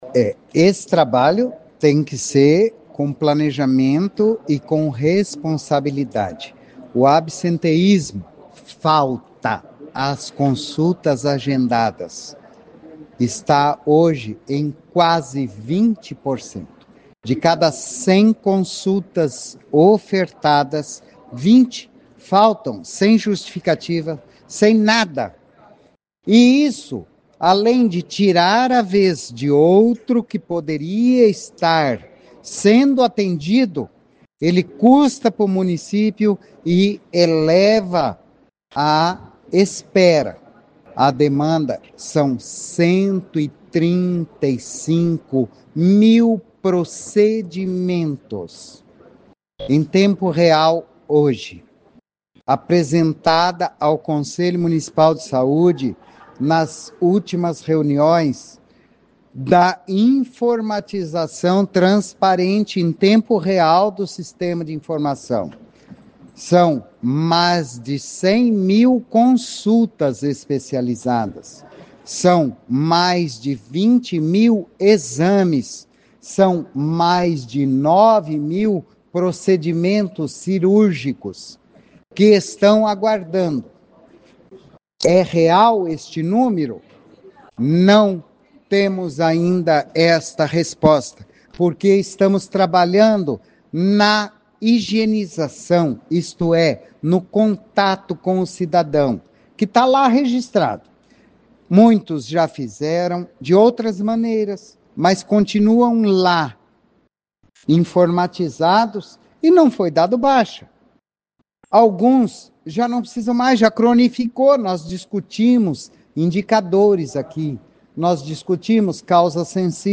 Ouça a entrevista coletiva com o secretário Antônio Carlos Nardi: